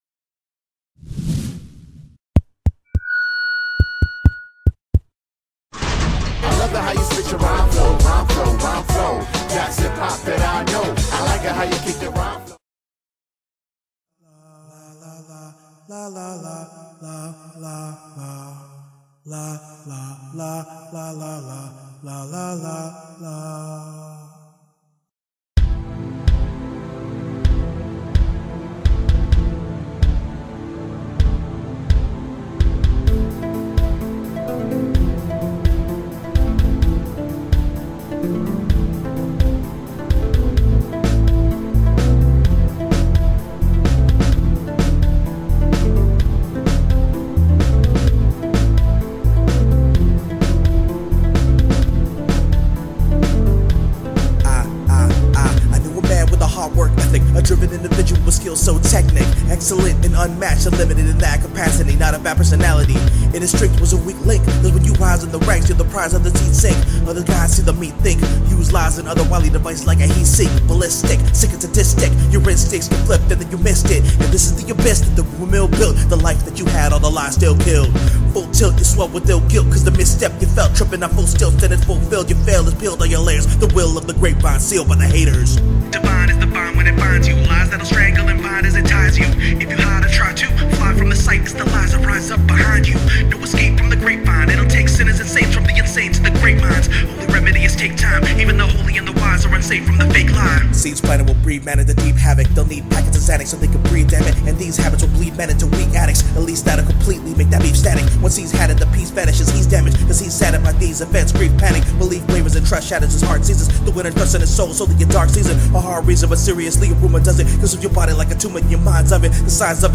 brooding instrumental